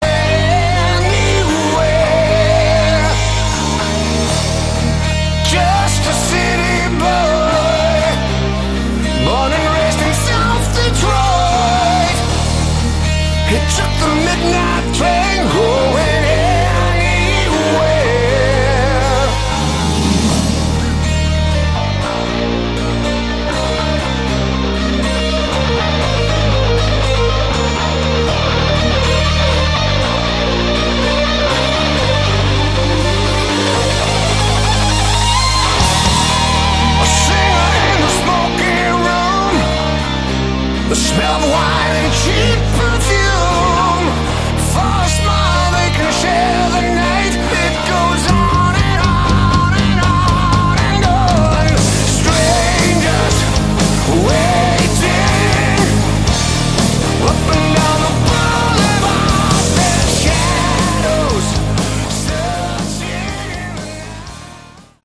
Category: Melodic Metal
vocals